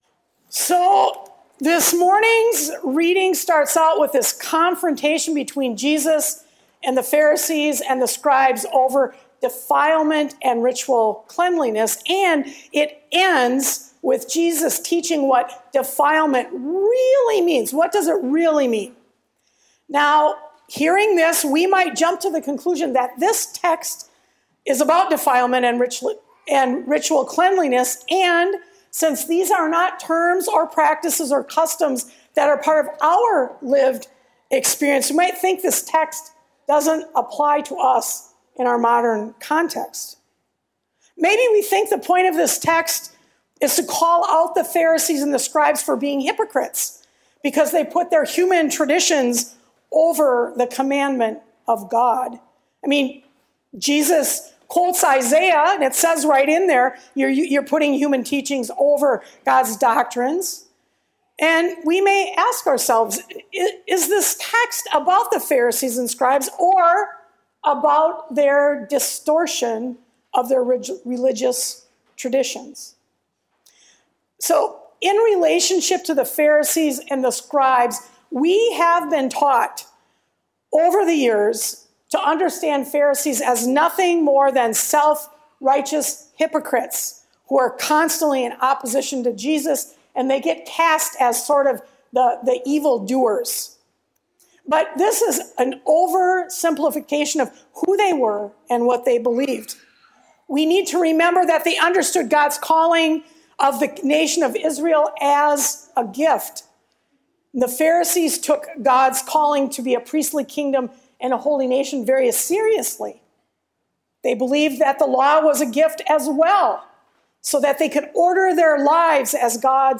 Sermons | Eleva Lutheran Church